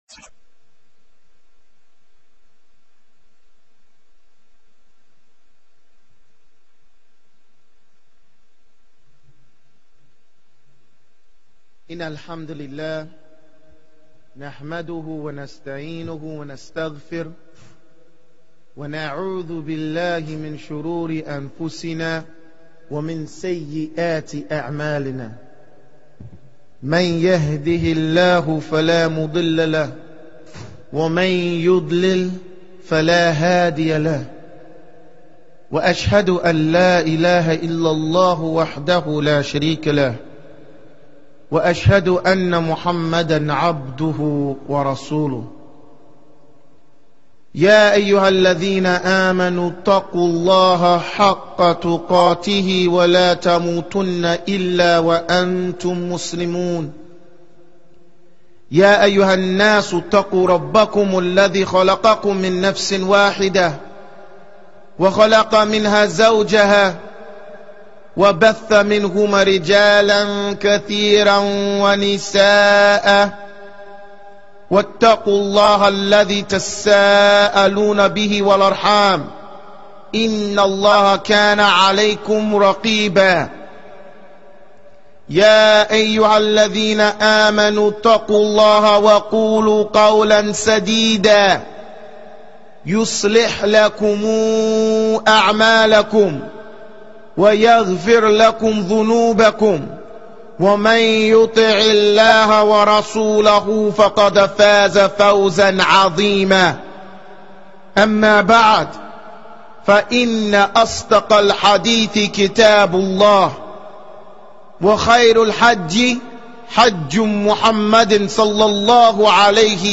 Jumuah Khutbah